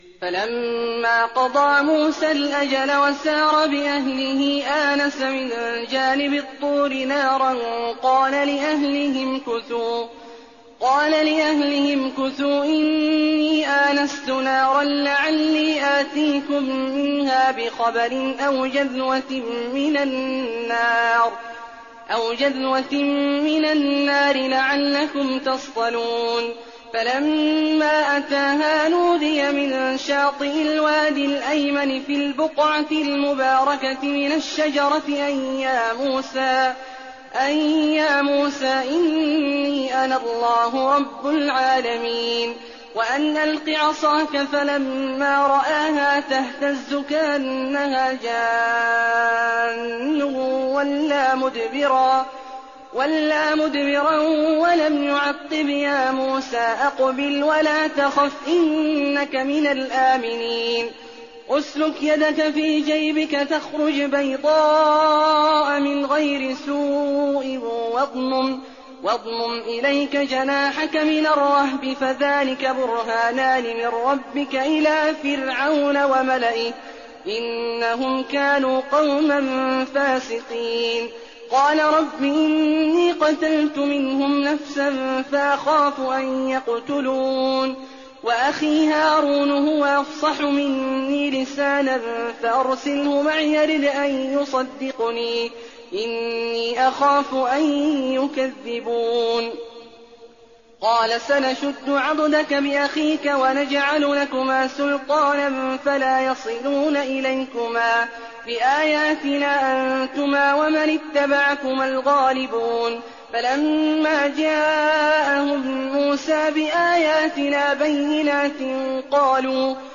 تراويح الليلة التاسعة عشر رمضان 1419هـ من سورتي القصص (29-88) والعنكبوت (1-35) Taraweeh 19th night Ramadan 1419H from Surah Al-Qasas and Al-Ankaboot > تراويح الحرم النبوي عام 1419 🕌 > التراويح - تلاوات الحرمين